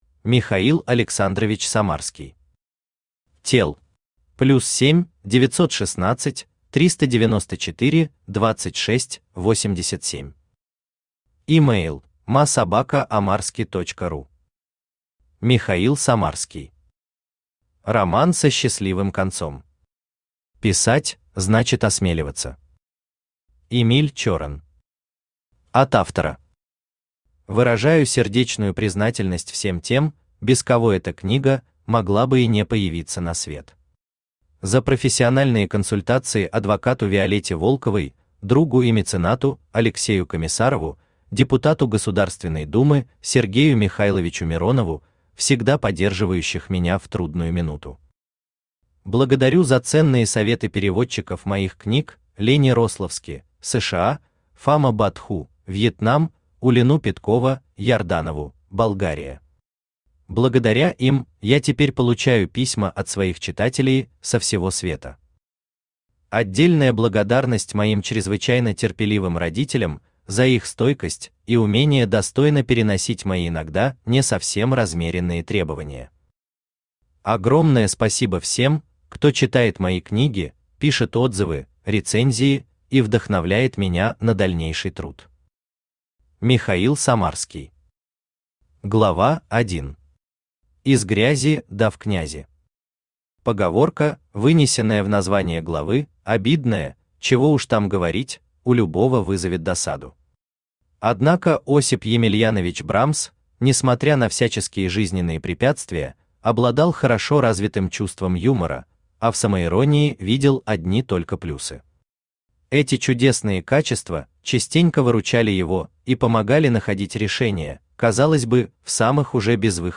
Aудиокнига Роман со счастливым концом Автор Михаил Самарский Читает аудиокнигу Авточтец ЛитРес.